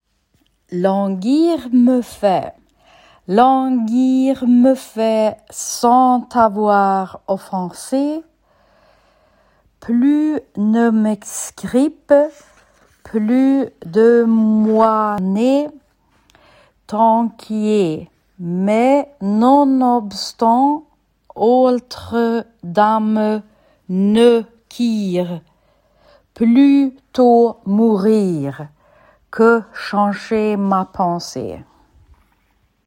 Languir me fais – uttal
Languir-me-fais-–-uttalsforslag.mp3